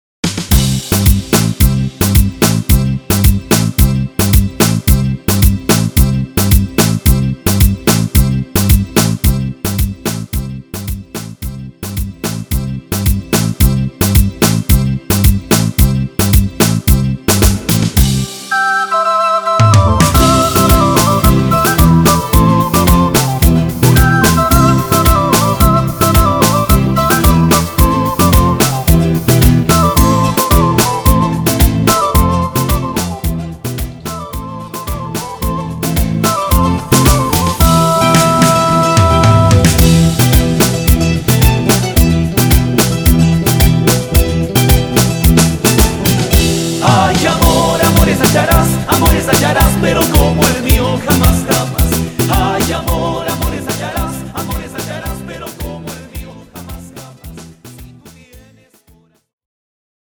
Etiqueta: Foklore